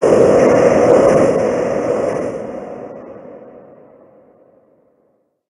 Cri de Lokhlass Gigamax dans Pokémon HOME.
Cri_0131_Gigamax_HOME.ogg